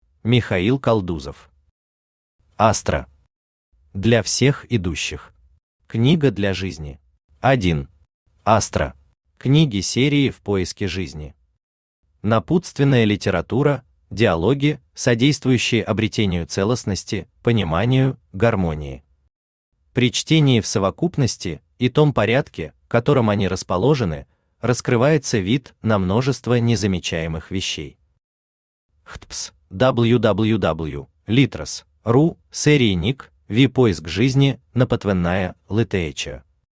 Аудиокнига Астра. Для всех идущих. Книга для жизни | Библиотека аудиокниг
Книга для жизни Автор Михаил Константинович Калдузов Читает аудиокнигу Авточтец ЛитРес.